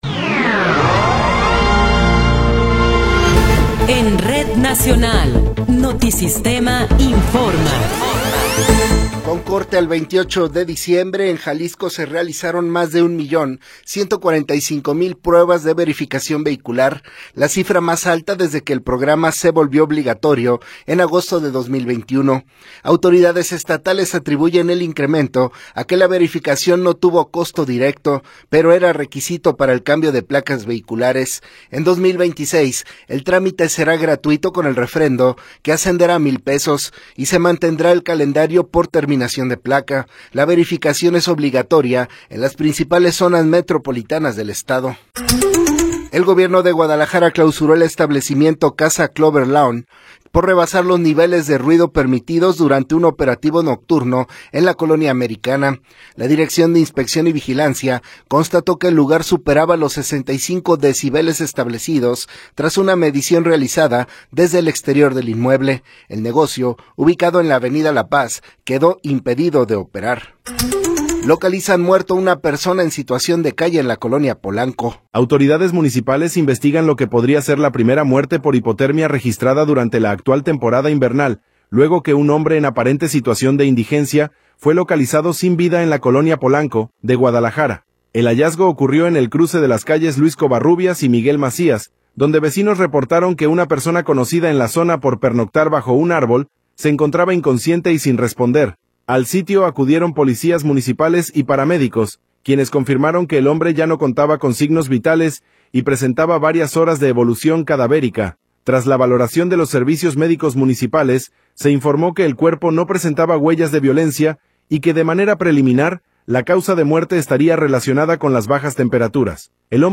Noticiero 13 hrs. – 31 de Diciembre de 2025